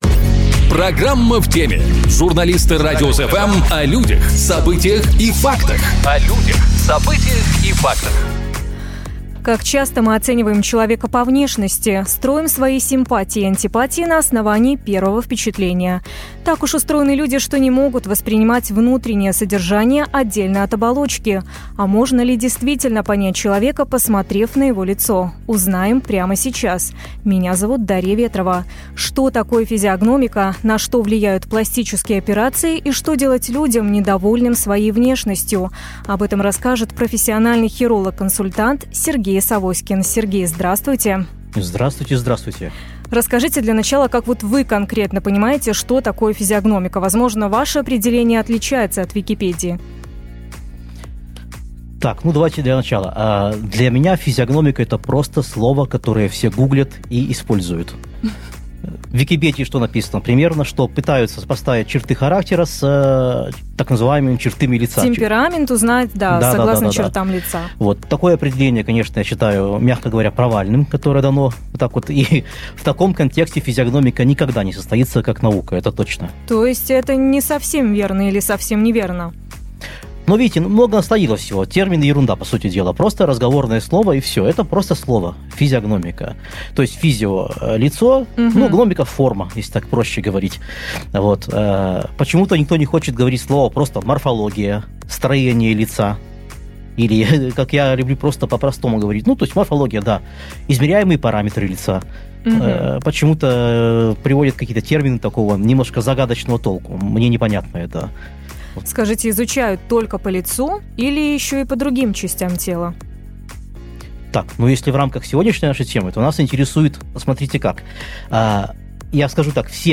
Об этом расскажет профессиональный хиролог-консультант